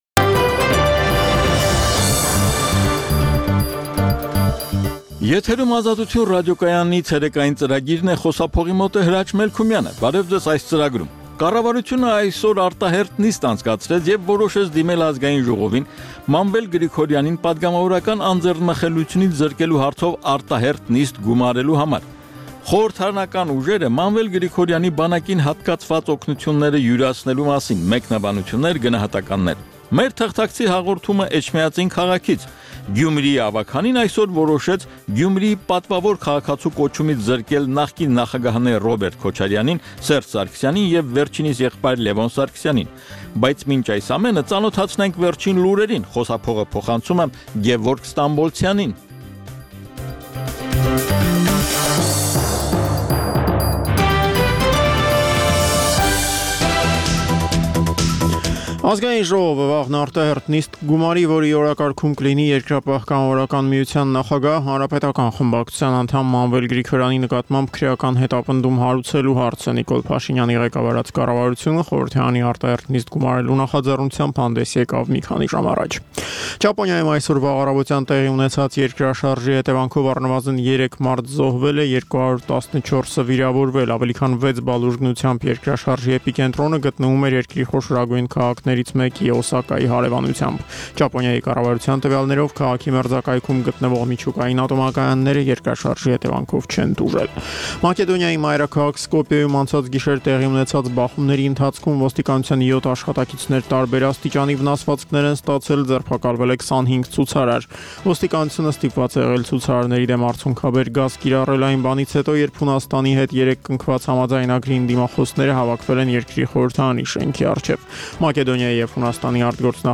«Ազատություն» ռադիոկայանի ցերեկային ծրագիր